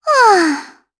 Estelle-Vox_Sigh_jp.wav